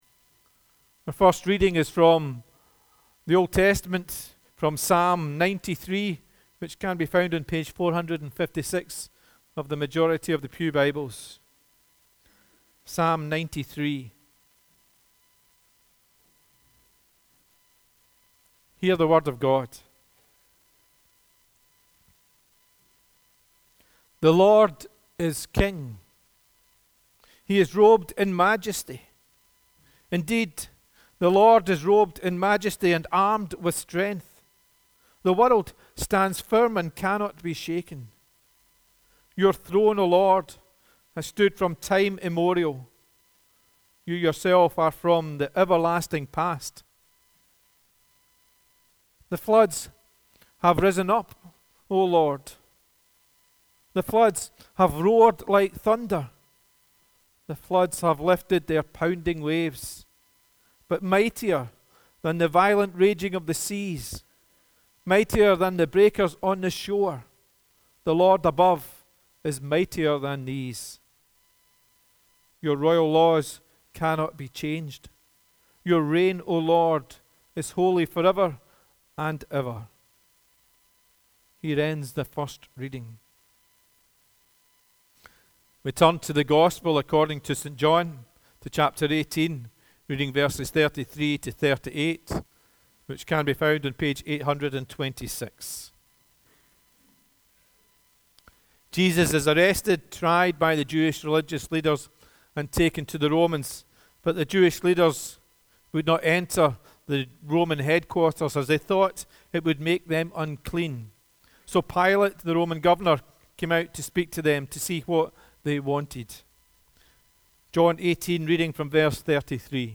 The Scripture Readings prior to the Sermon are Psalm 93 and John 18: 33-38